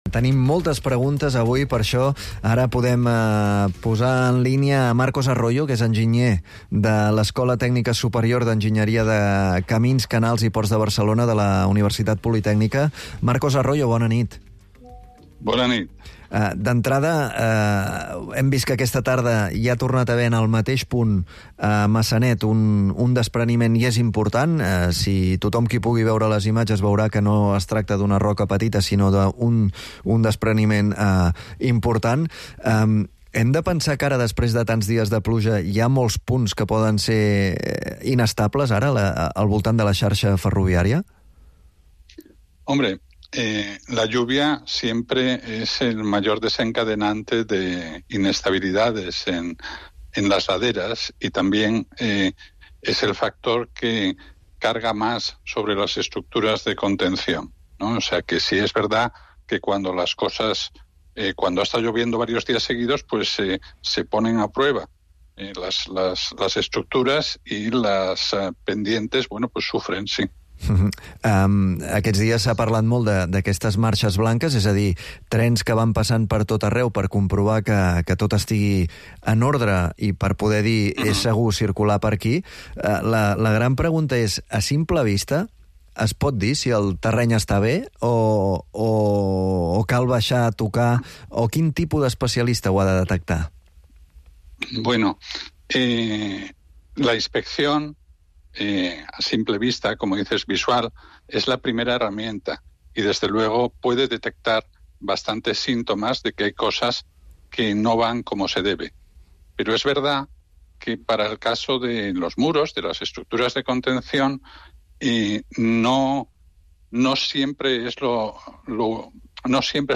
The expert has shared with 3CatInfo, Catalunya Ràdio and the Ara newspaper the key factors that can compromise the stability of a retaining wall.